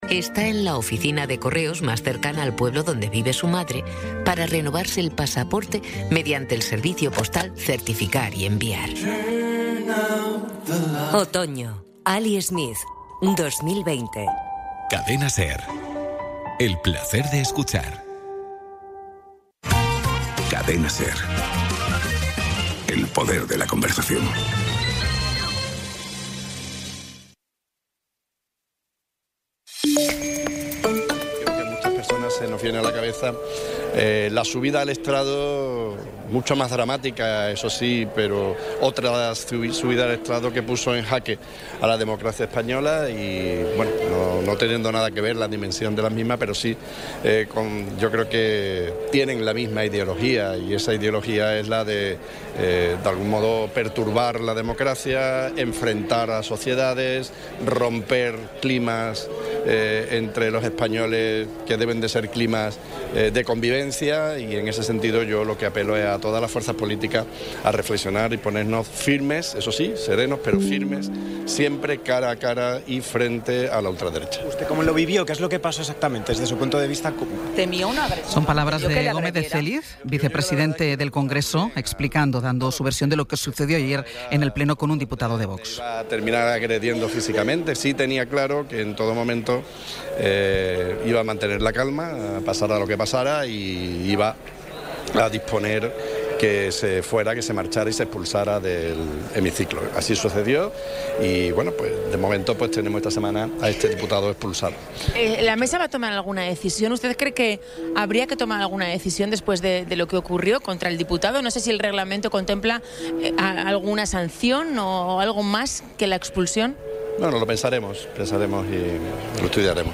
Resumen informativo con las noticias más destacadas del 15 de abril de 2026 a las nueve de la mañana.